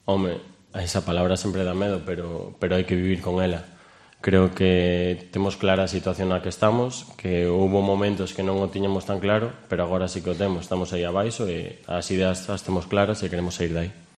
El canterano ha pasado por la sala de prensa de A Madroa tras el entrenamiento para hacer balance de la situación que atraviesa el equipo.